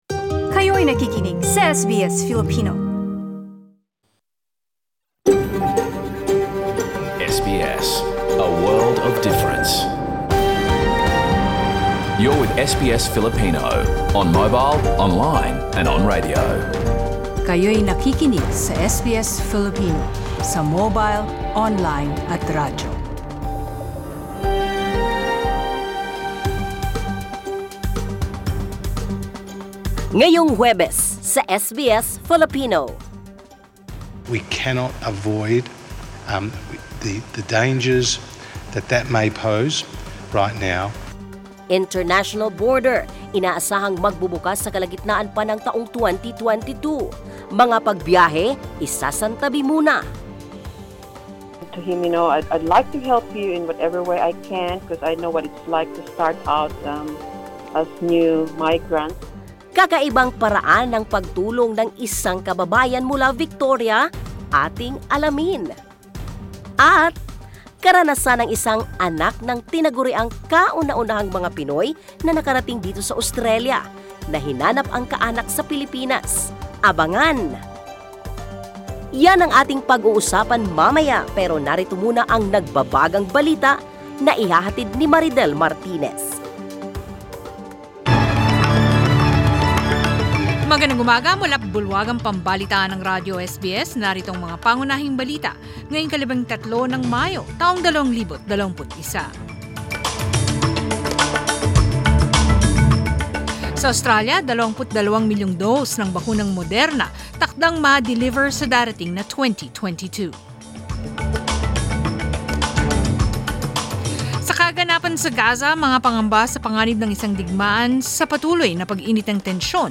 Balita ngayon ika 13 ng Mayo